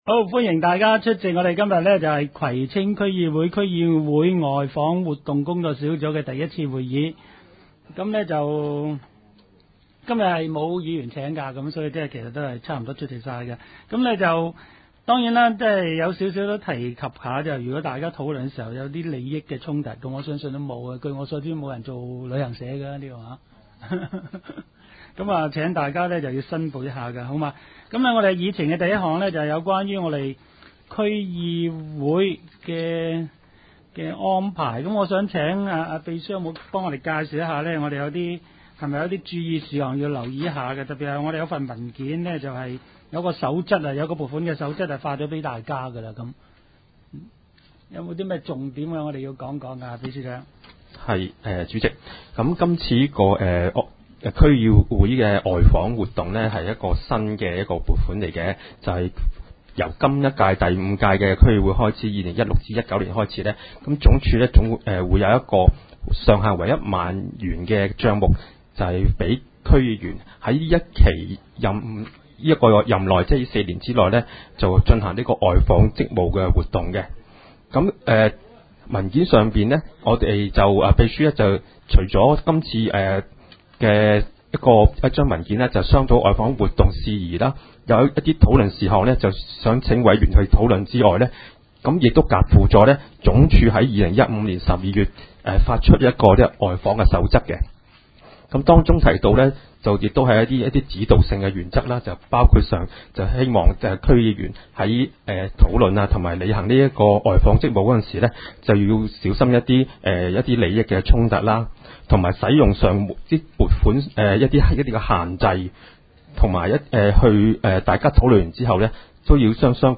工作小组会议的录音记录
地点: 葵青民政事务处会议室